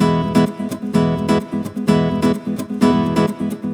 VEH2 Nylon Guitar Kit 128BPM
VEH2 Nylon Guitar Kit - 15 E maj.wav